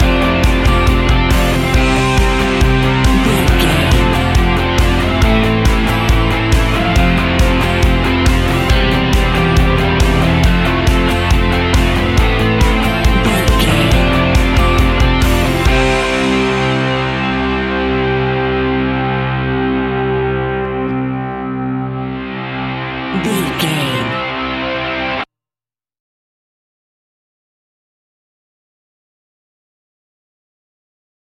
Ionian/Major
energetic
driving
heavy
aggressive
electric guitar
bass guitar
drums
indie pop
uplifting
piano
organ